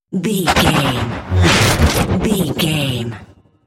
Sci fi gun shot hit whoosh
Sound Effects
heavy
intense
dark
aggressive